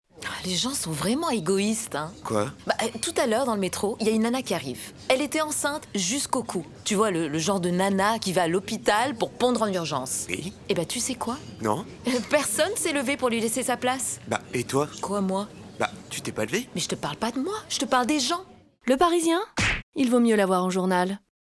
Pub DM - Voix jouée
1.Demo-Le-Parisien-Pub-Voix-Jouee.mp3